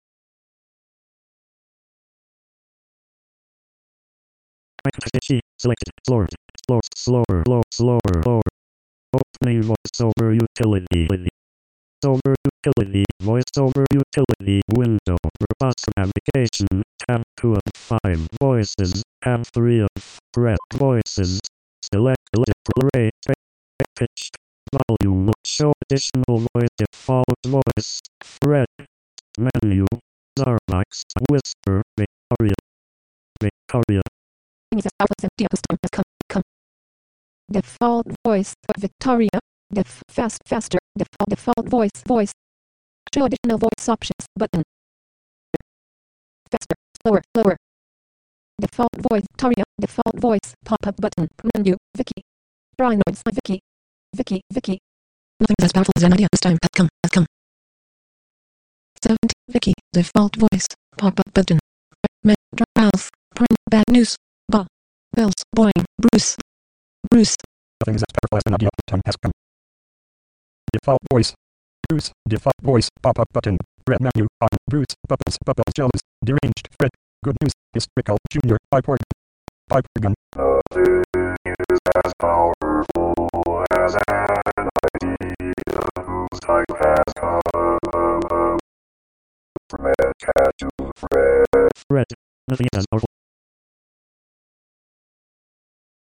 Still on a long-forgotten quest to get Mac OS 10.4 tiger up and running in virtual machine form for the old Fred voice that Apple used Pre Mac OS 10.5. Who knows when it'll happen, but ever so often I think of it.
Well, thing is, I did get this running in UTM/Qemu, but... Well... The good news is I *did* install Audio Hijack on there and was able to record glitch free audio that way.